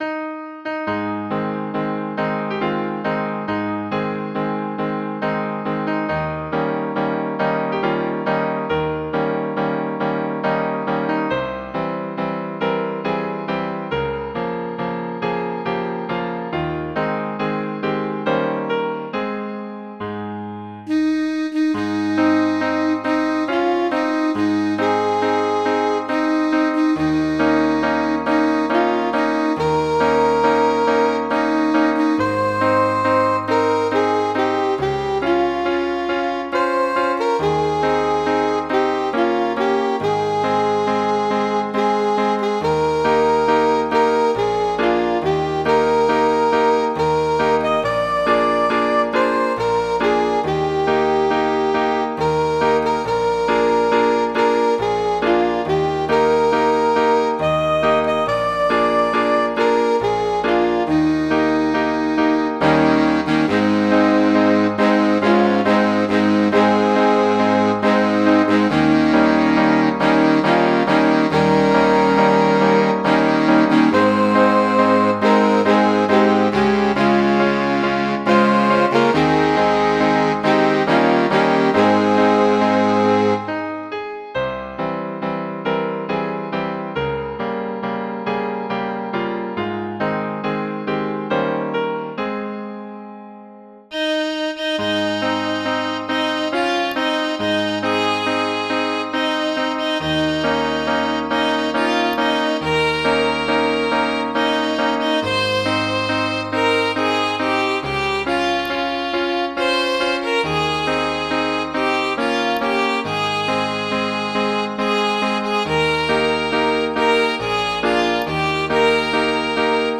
Midi File, Lyrics and Information to The Vacant Chair